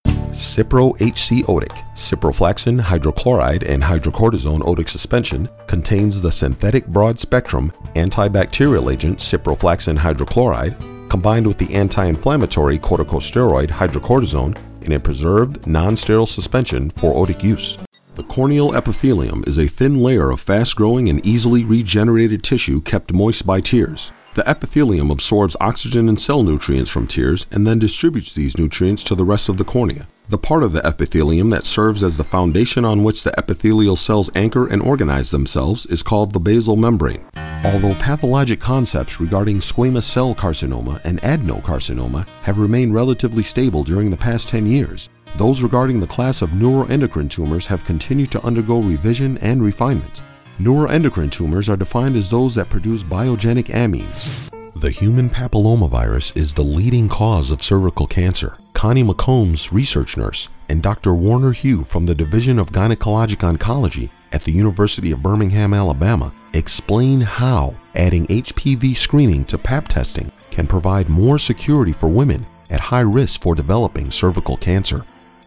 voice is a strong baritone with highly authoritative and persuasive characteristics. It’s a sound that embodies confidence and power, with tones that are warm and inviting.
His versatility enables him to smoothly transition from an unaccented middle-American feel to roles requiring an urban contemporary voice. His read is very well-suited for the requirements of corporate and professional work, but when called for, he can display a relaxed conversational style and a natural sense of humor.
Sprechprobe: Sonstiges (Muttersprache):